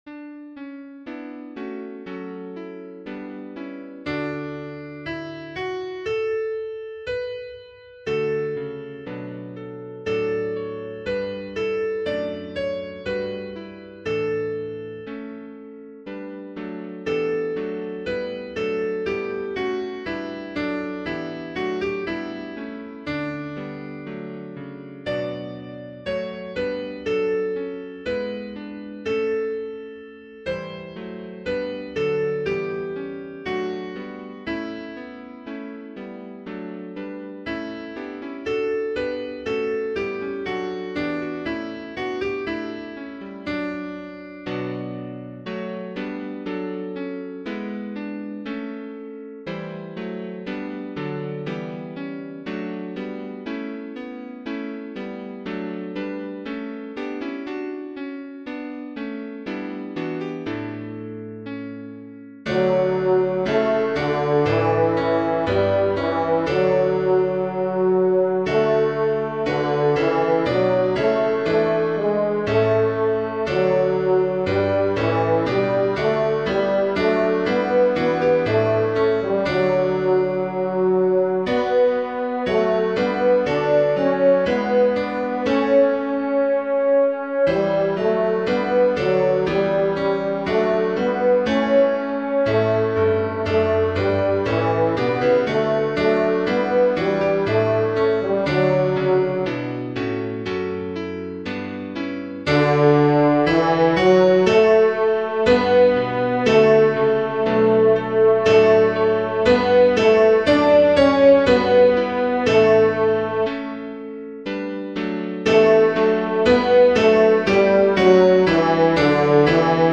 Practice tracks used with the permission of Hope Publishing Company, which owns the copyright on this arrangement.
come_down_o_love_divine-tenor.mp3